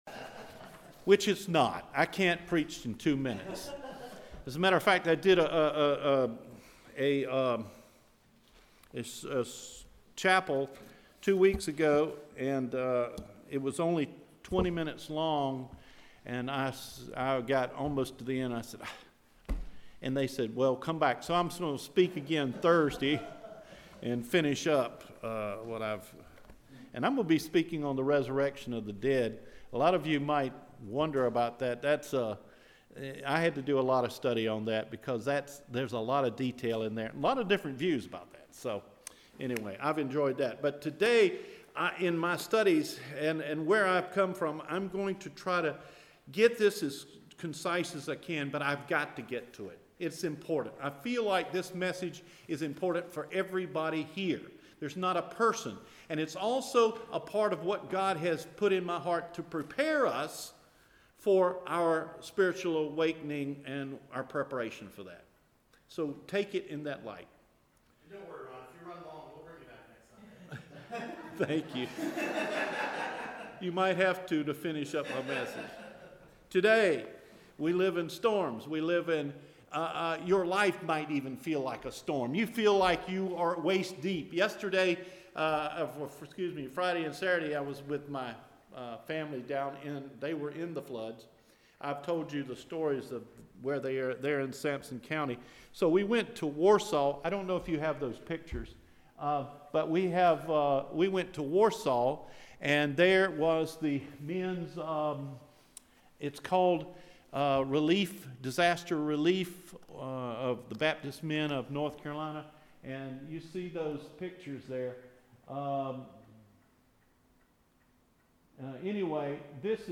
Living Through Storms – September 30 Sermon